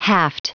Prononciation du mot haft en anglais (fichier audio)
Prononciation du mot : haft